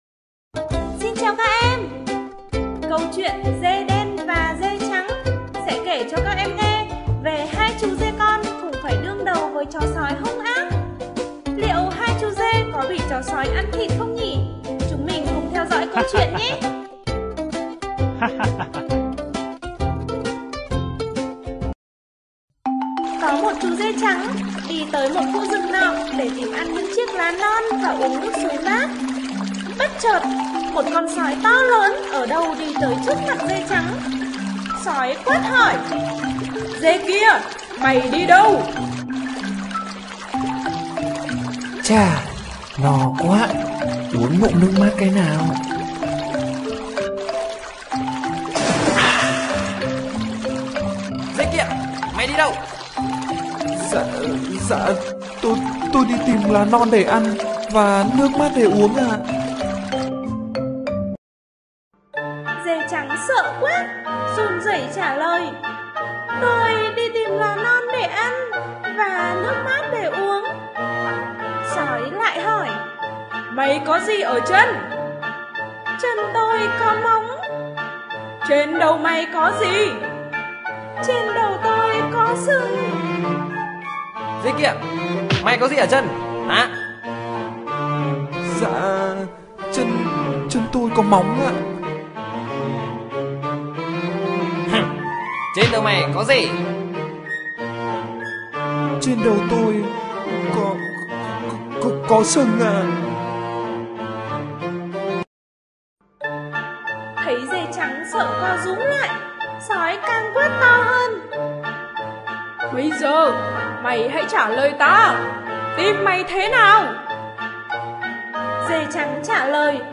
Sách nói | Truyện: Dê đen và dê trắng